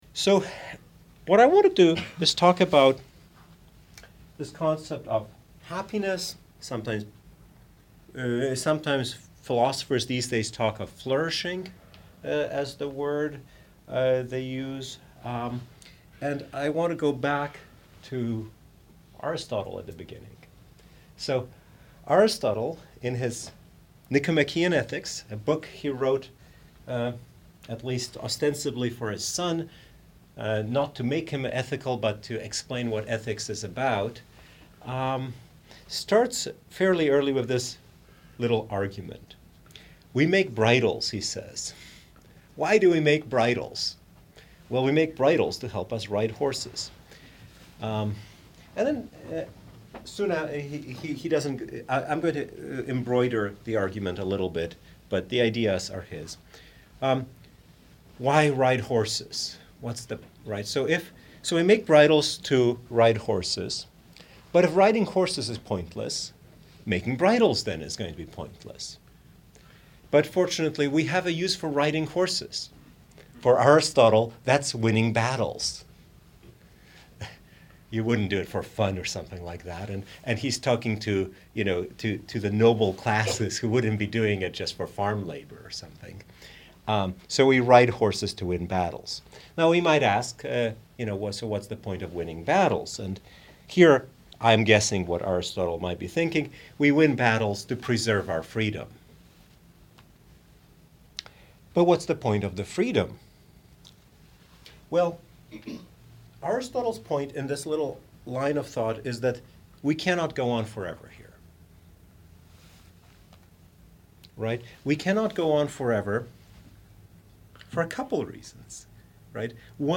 This lecture was given at Brown University on 18 October 2019.